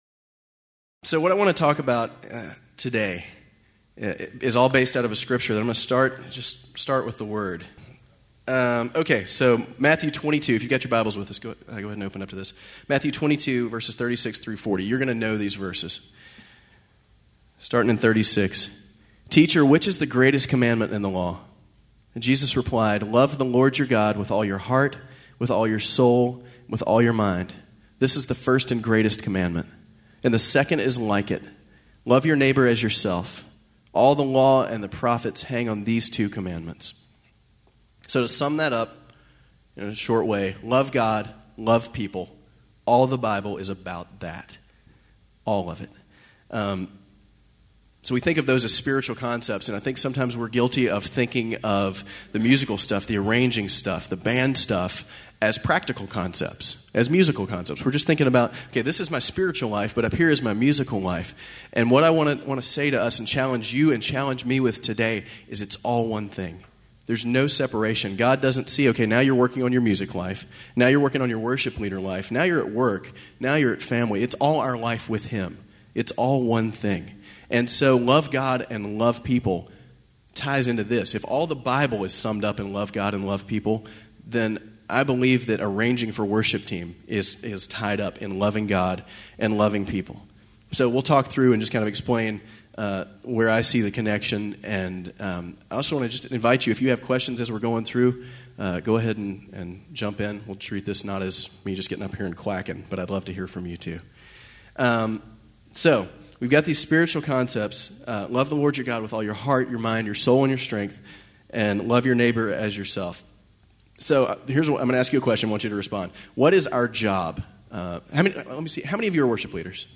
Series: 2006 Calvary Chapel Worship Leader Conference
Service Type: Workshop